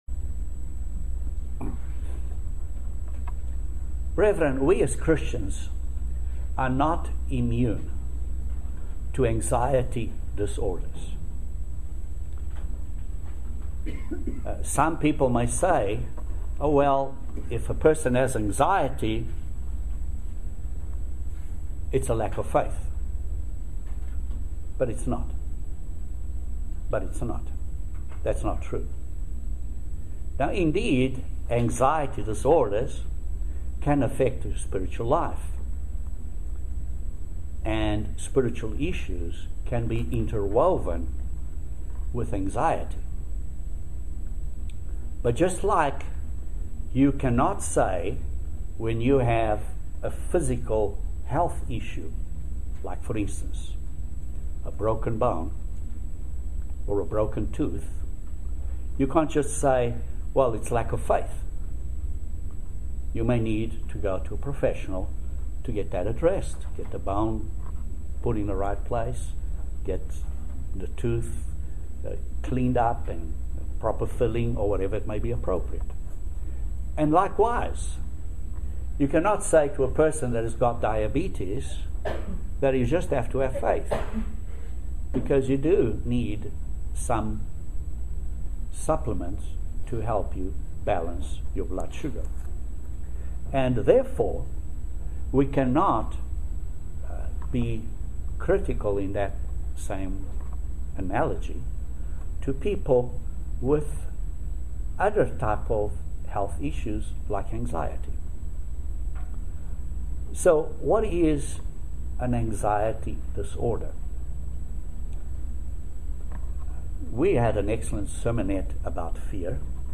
Join us for this very interesting video sermon about Anxiety, and what we can do to alleviate these problems, and help others around us. These problems do exist in the church and outside also.